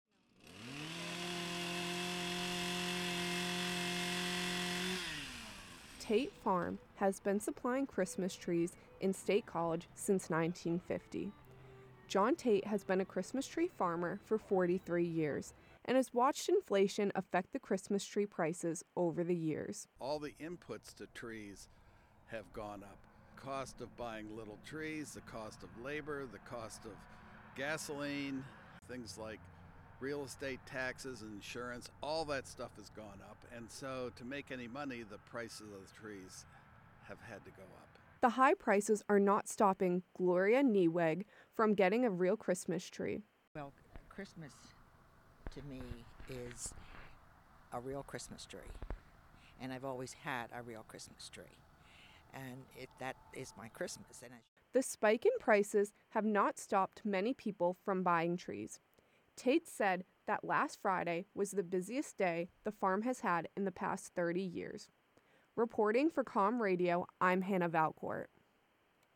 Christmas Tree Farm Wrap